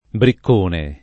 [ brikk 1 ne ]